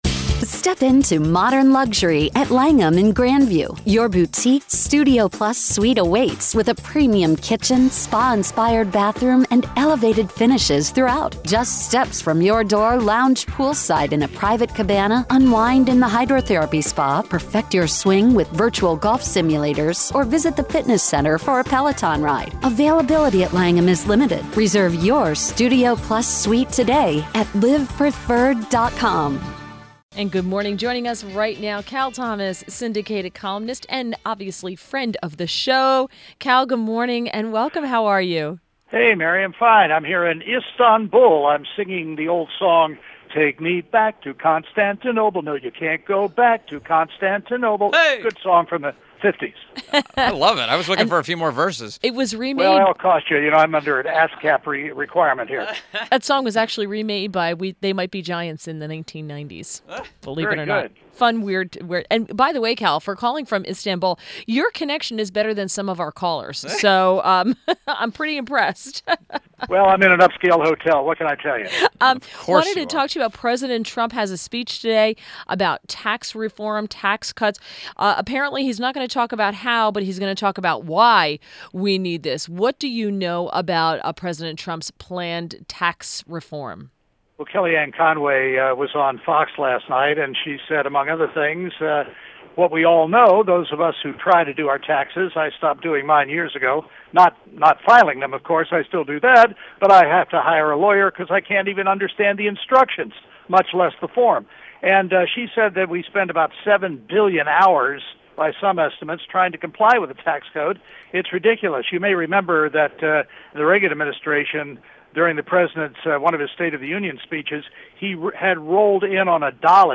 INTERVIEW – CAL THOMAS – Syndicated columnist